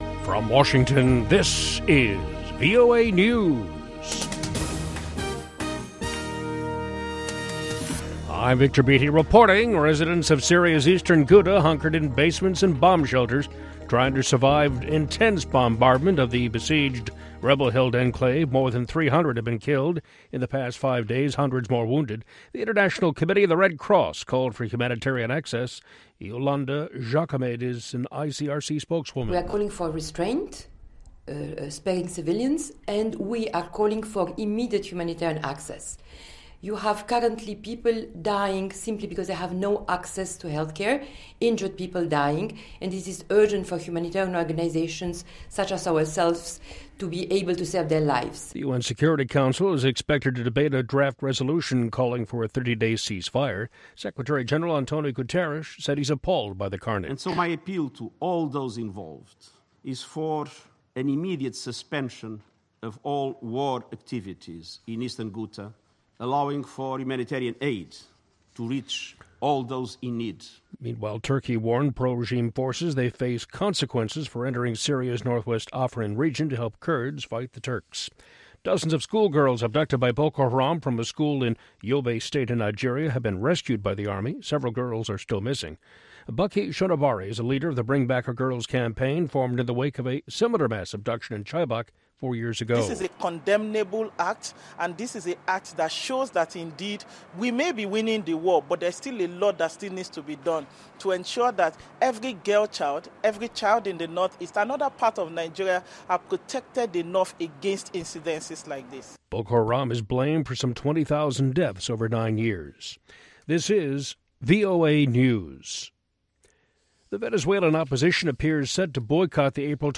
African Beat showcases the latest and the greatest of contemporary African music and conversation. From Benga to Juju, Hip Life to Bongo Flava, Bubu to Soukous and more